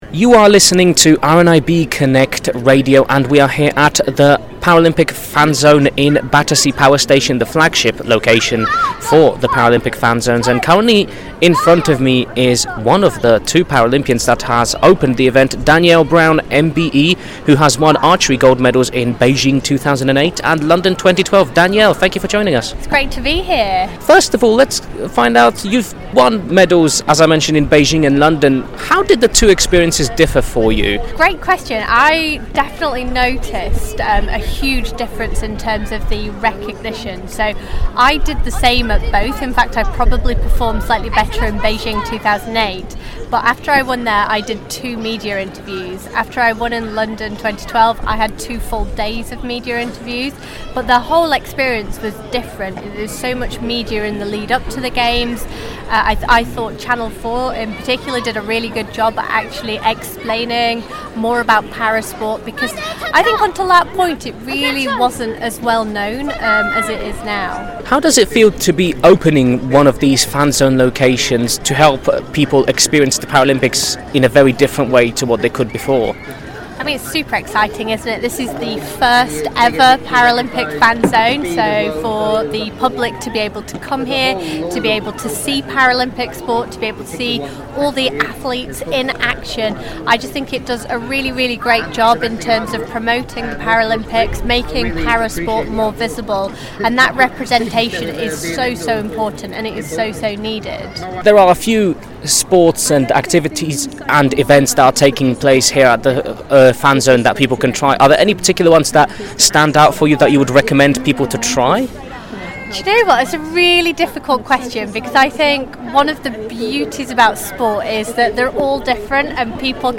Paralympic Fan Zone At Battersea Power Station: Chat with Danielle Brown MBE